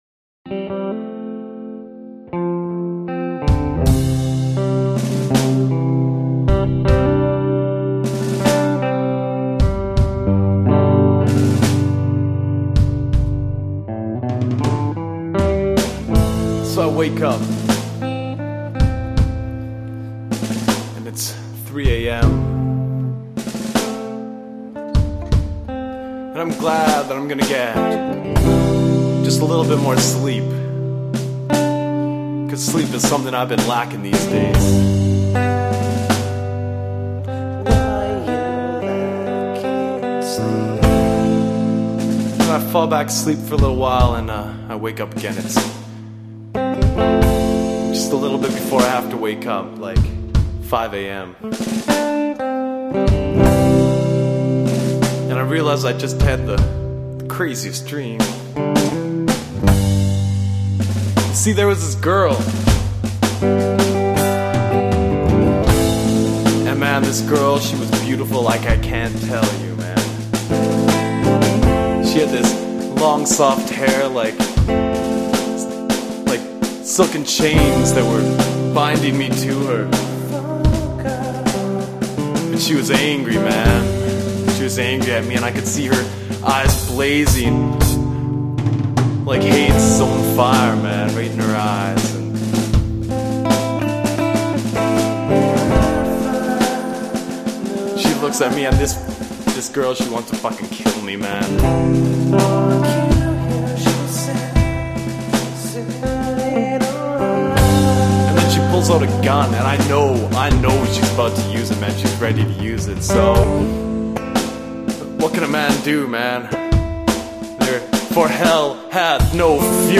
Avantgarde
Main Vocals
Backup Vocals
Electric Guitar
Drums